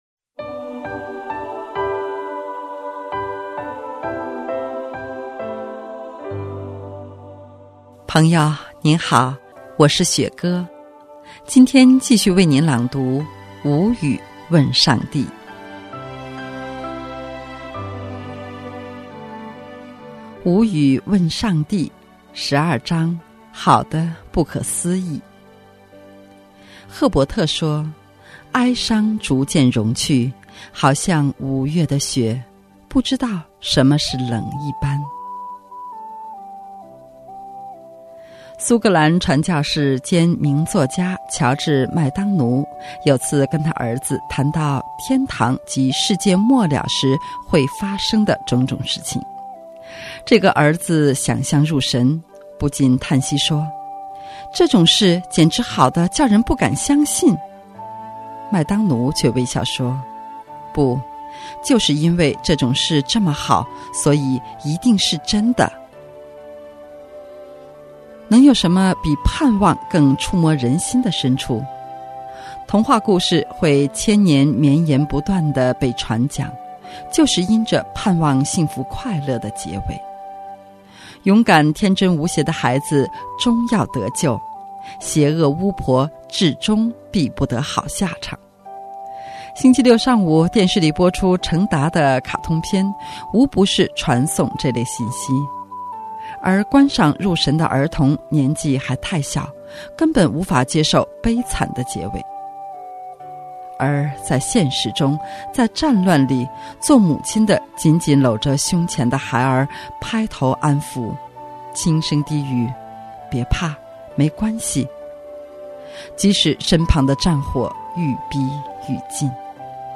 首页 > 有声书 > 福音 > 无语问上帝 | 有声书 | 福音 > 无语问上帝 12：好的不可思议